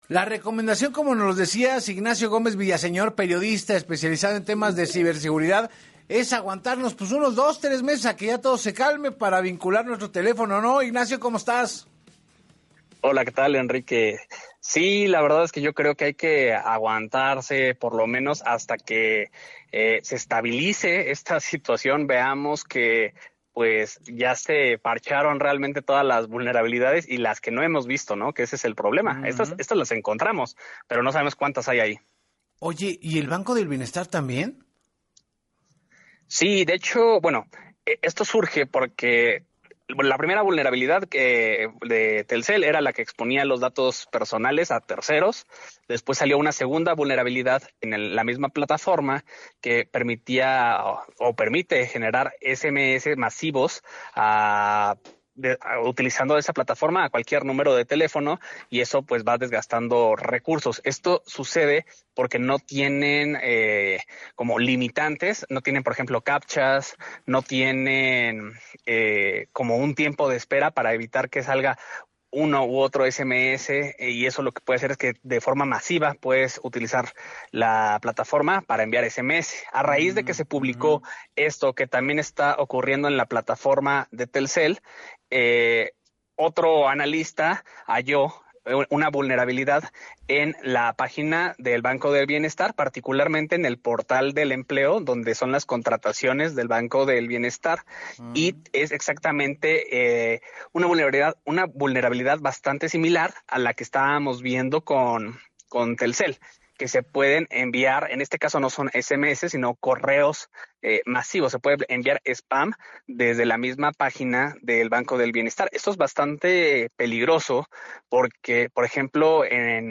En entrevista para “Así Las Cosas con Enrique Hernández Alcázar”